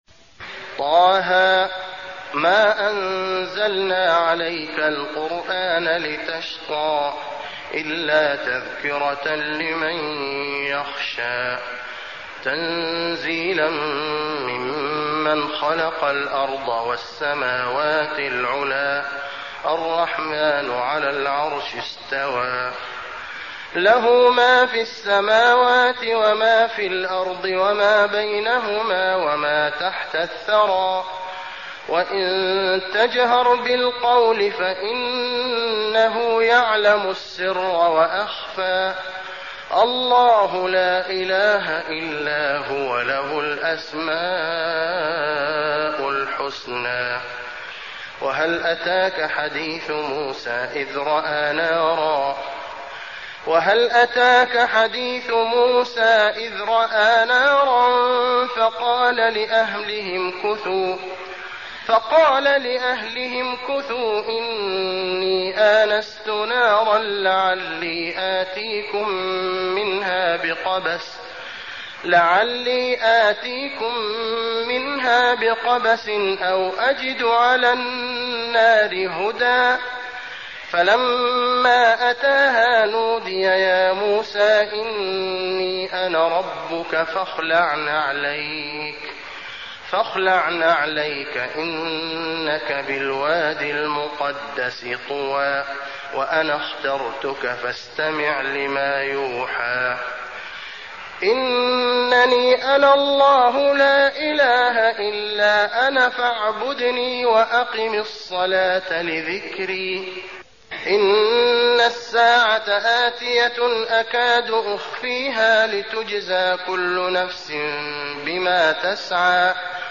المكان: المسجد النبوي طه